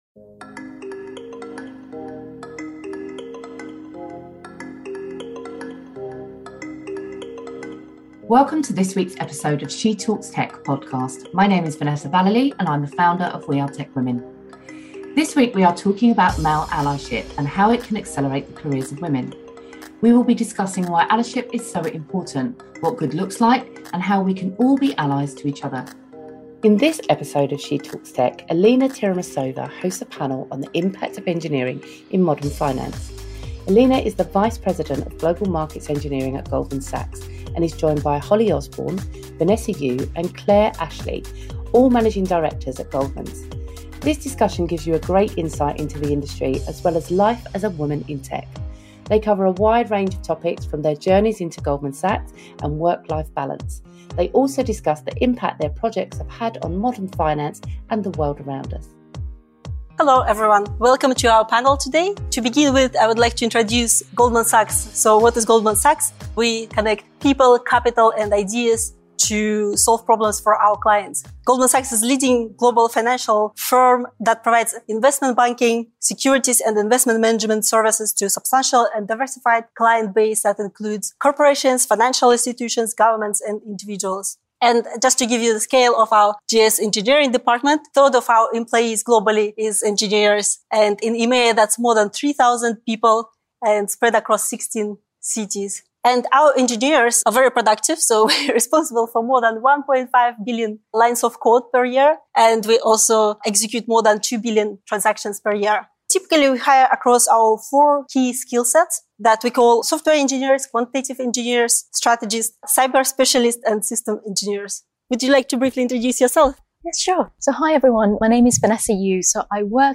Engineering in Modern Finance with a panel from Goldman Sachs